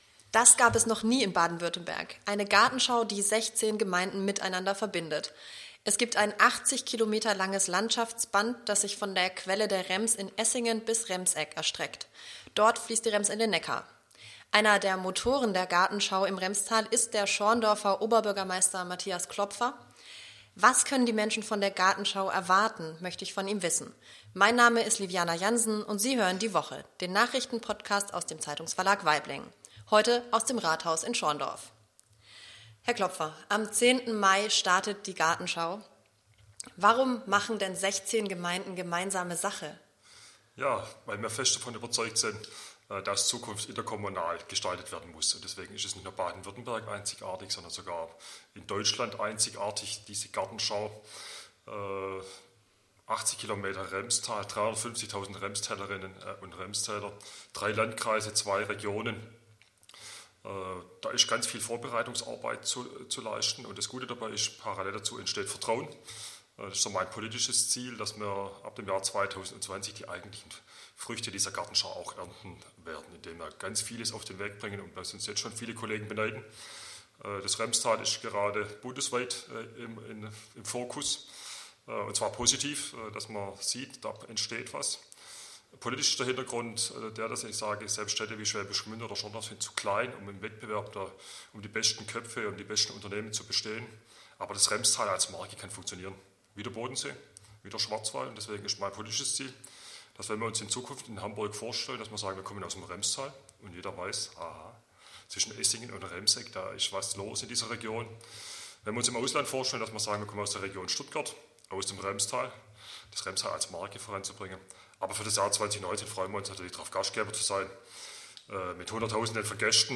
Die Woche ist der Nachrichten-Podcast aus dem Zeitungsverlag Waiblingen.